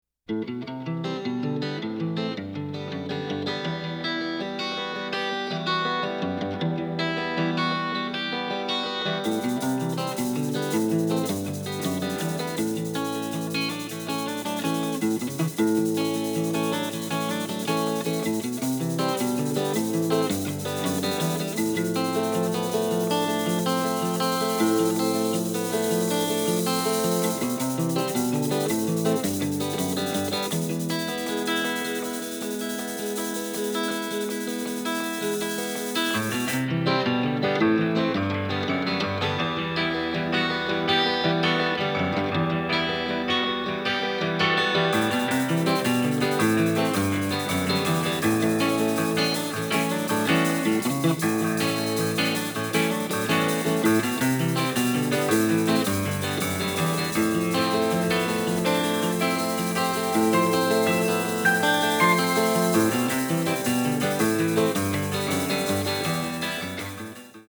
and the result was an album recorded mostly in New York City
rocksteady bass